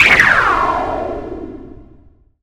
windshield.wav